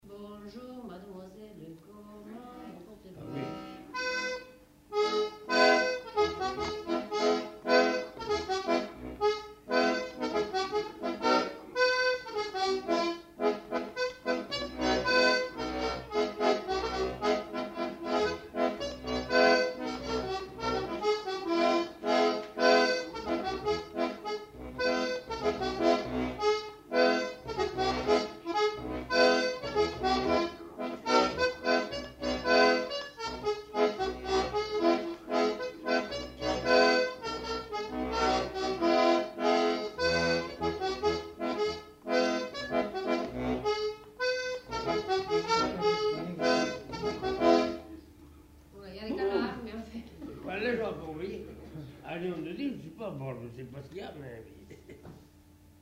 Lieu : Pavie
Genre : morceau instrumental
Instrument de musique : accordéon diatonique
Danse : polka piquée